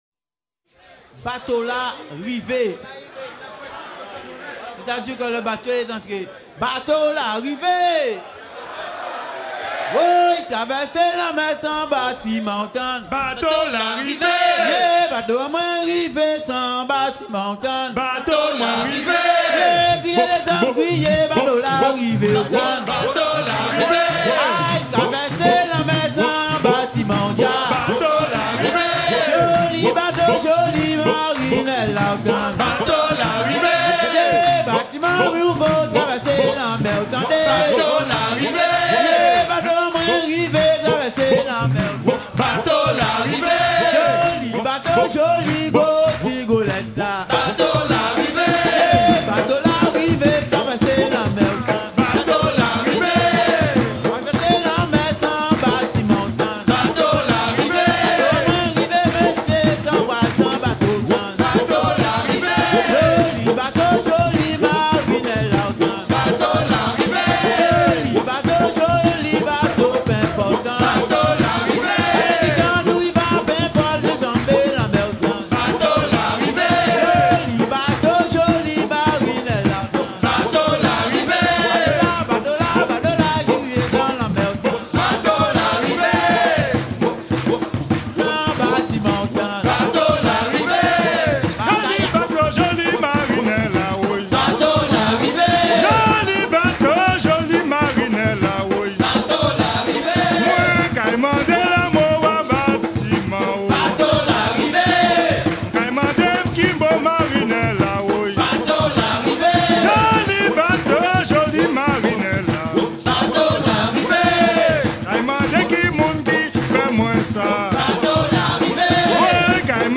chanson liée à la musique Gwo-Ka
Pièce musicale éditée